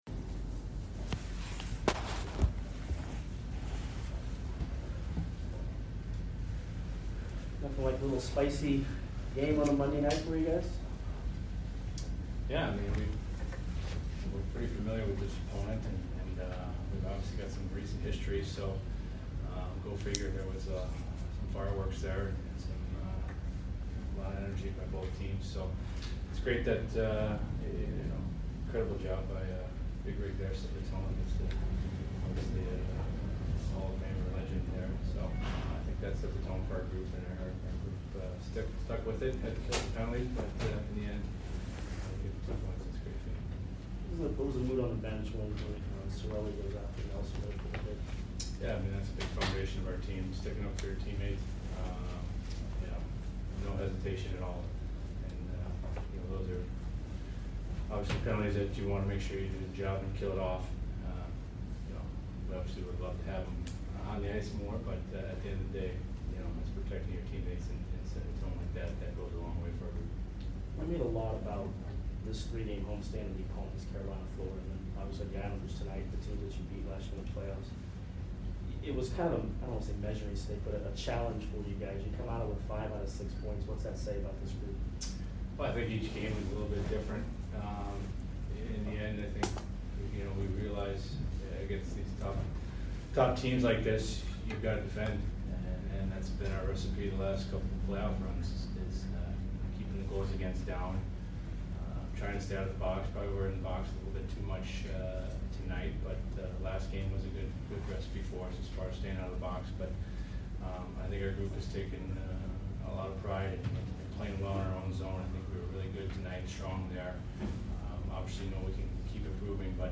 Ryan McDonagh Post Game Vs NYI 11 - 15 - 21